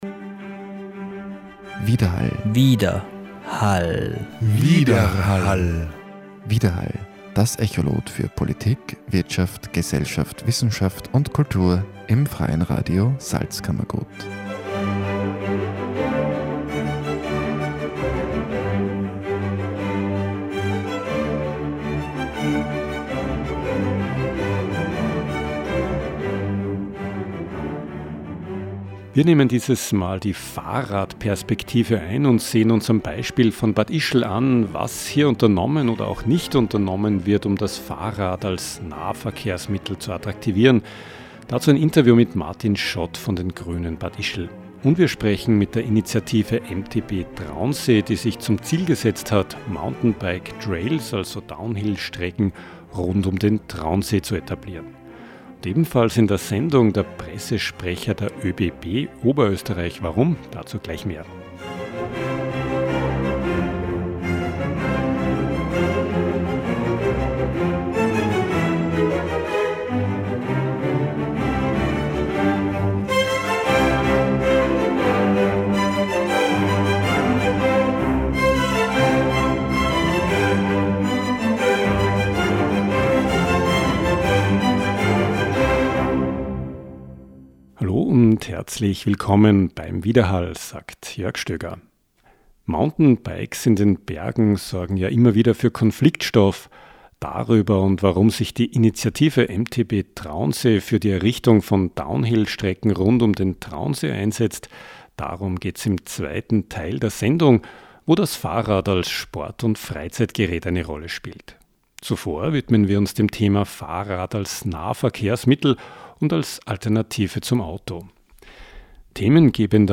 Telefongespräch